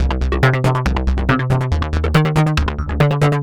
tx_synth_140_disco_CEbD1.wav